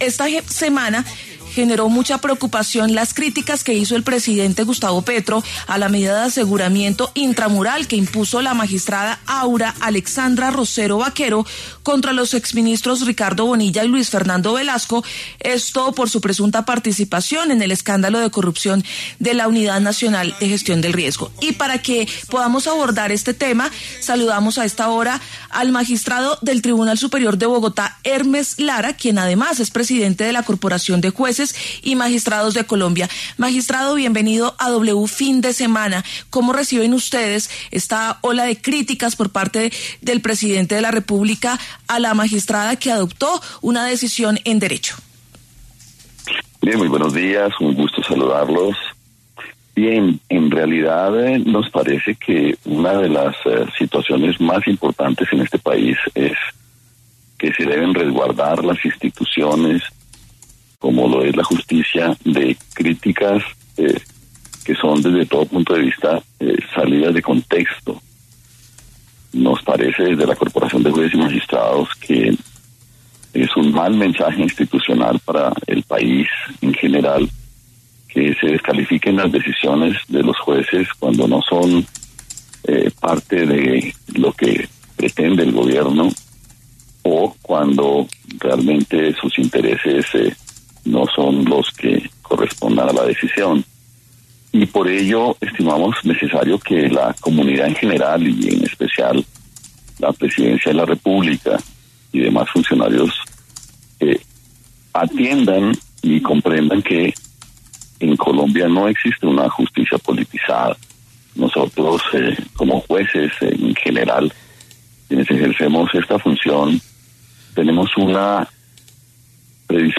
El magistrado Hermes Lara habló en W Fin de Semana sobre las críticas del presidente Gustavo Petro a la medida de aseguramiento intramural impuesta contra los exministros Ricardo Bonilla y Luis Fernando Velasco por su presunta participación en el escándalo de corrupción de la UNGRD.